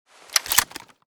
mossberg_reload.ogg.bak